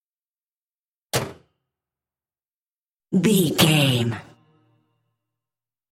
Car hood close
Sound Effects